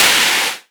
edm-clap-27.wav